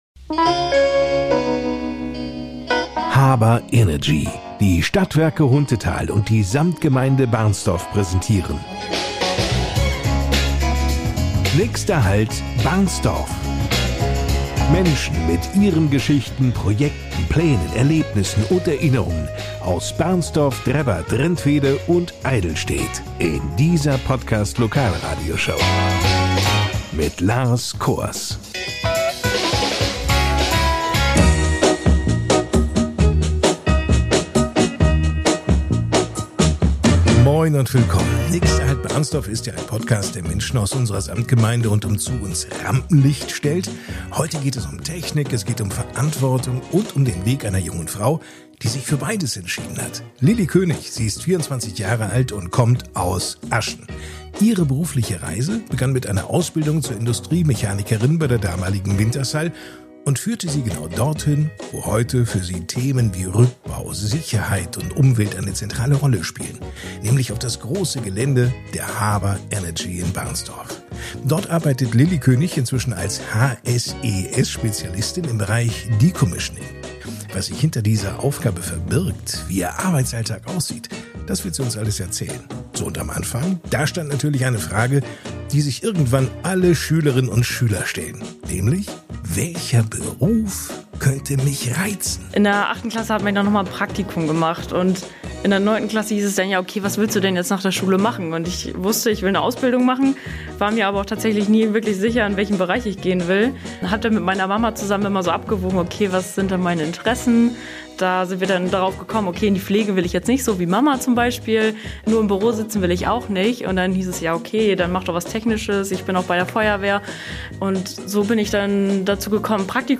Die Podcast-Lokalradioshow